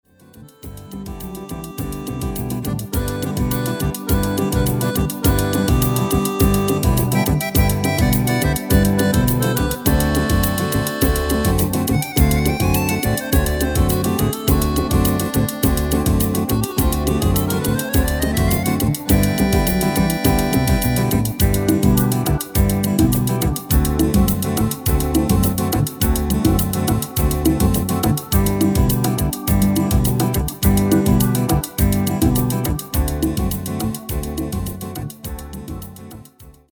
Demo/Koop midifile
Genre: Evergreens & oldies
Toonsoort: G
- Vocal harmony tracks